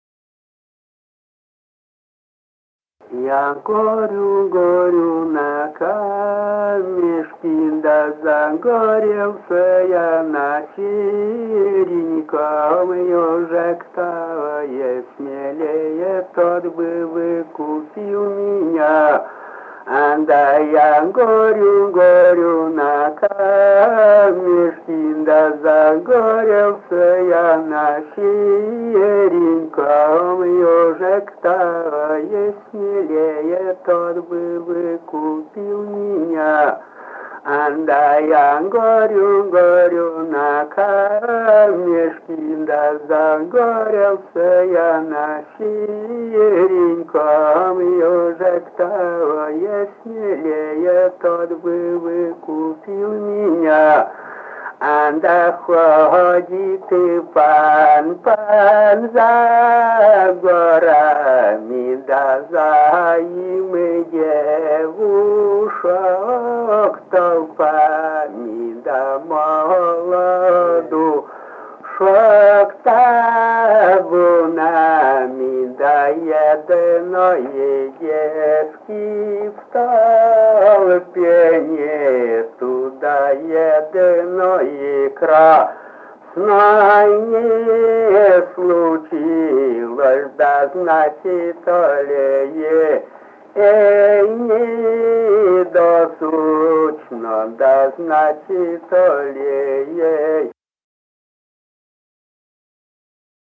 в д. Трусовская Усть-Цилемского р-на Коми АССР